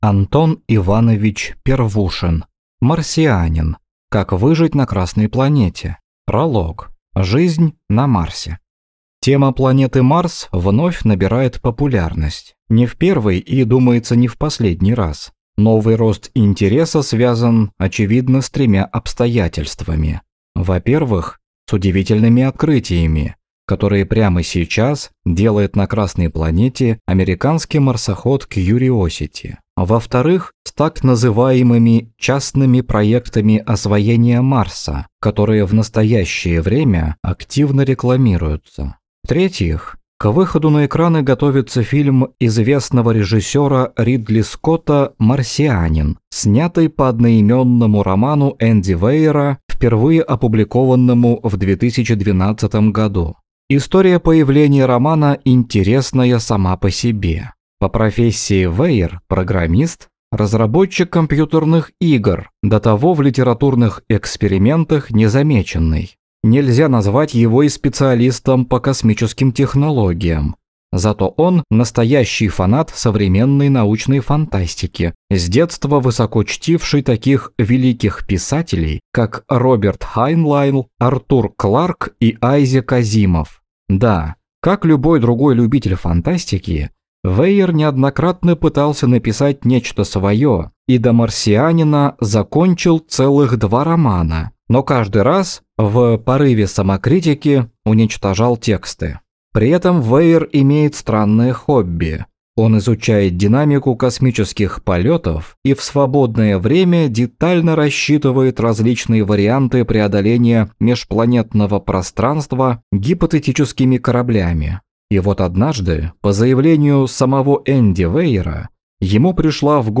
Аудиокнига Марсианин. Как выжить на Красной планете?
Прослушать и бесплатно скачать фрагмент аудиокниги